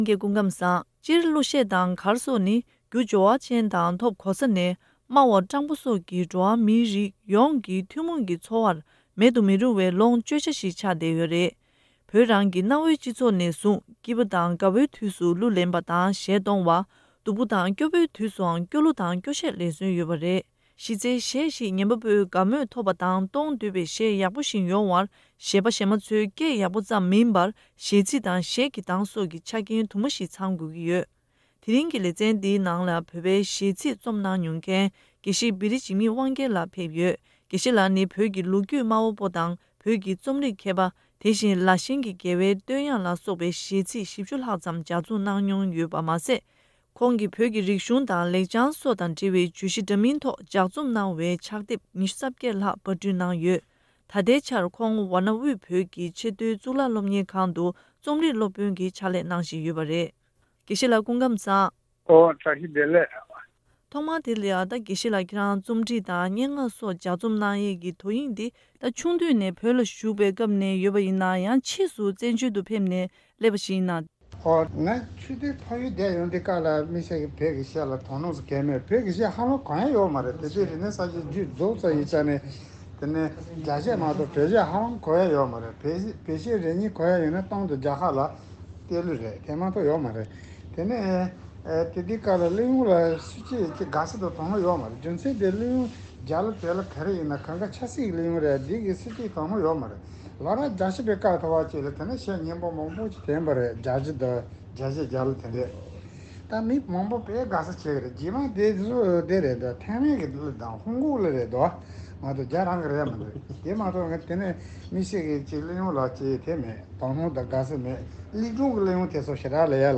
བཀའ་འདྲི་ཞུས་པའི་ལེའུ་དང་པོ་དེ་གསན་རོགས་གནང་།།